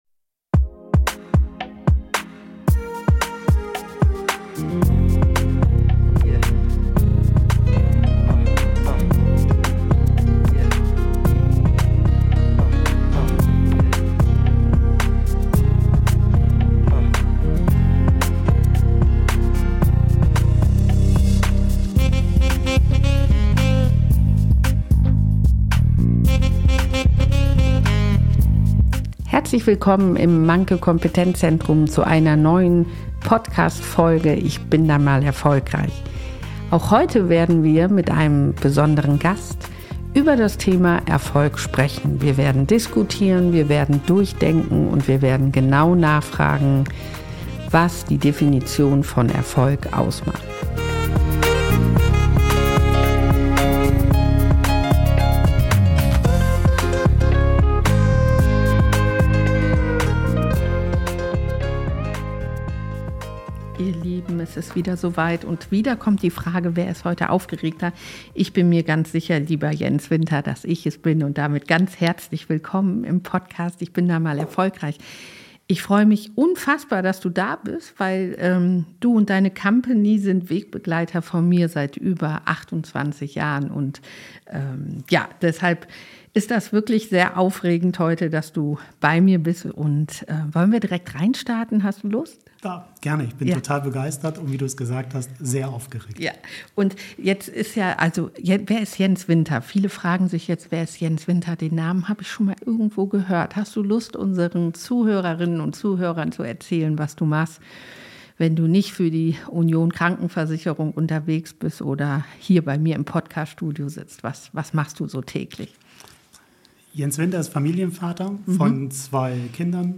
Im Gespräch mit uns erzählt er, wie seine eigene Reise begann: von einer Ausbildung als Kfz-Schlosser in der DDR, über erste Erfahrungen im Vertrieb, bis hin zu seiner heutigen Rolle als Führungskraft in der Sparkassenfinanzgruppe.